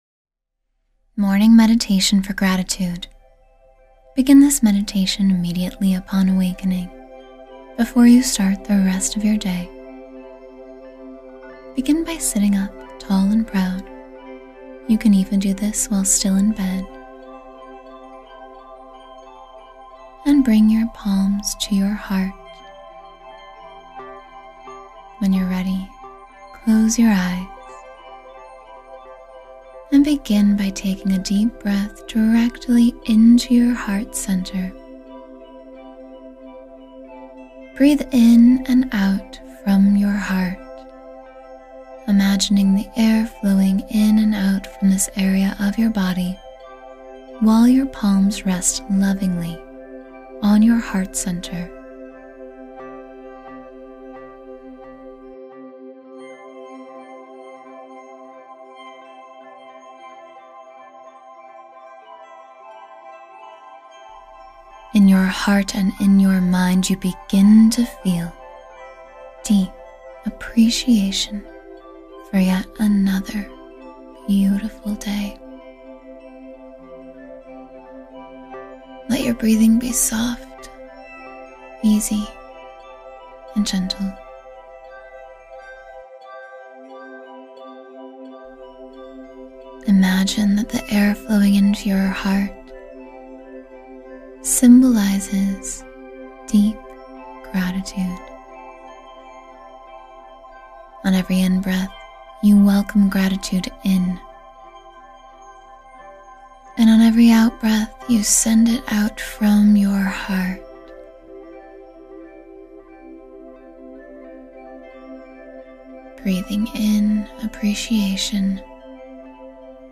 Begin the Day Grateful — A 10-Minute Morning Meditation for Abundance